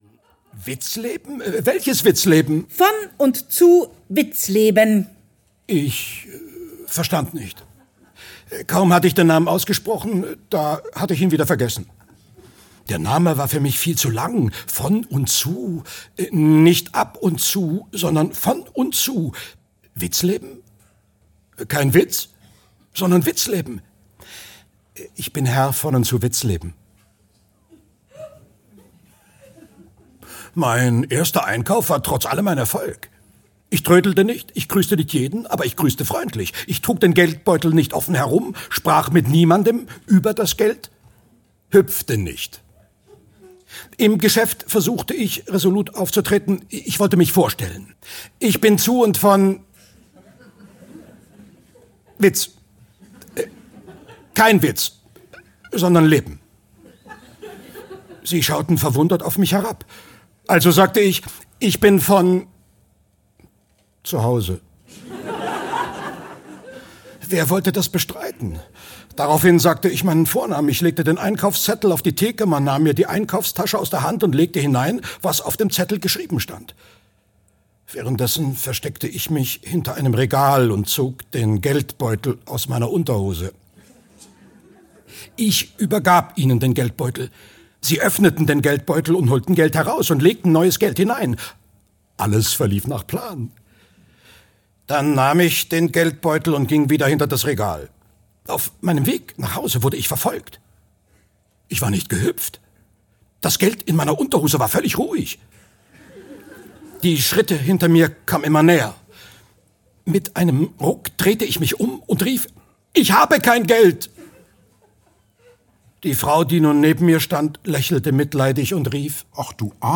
Die Würde des Lügens - Joachim Zelter - Hörbuch